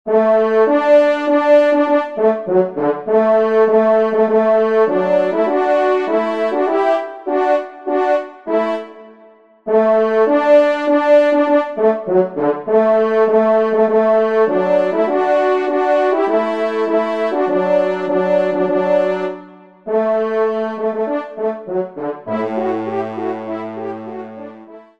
Cor d’Harmonie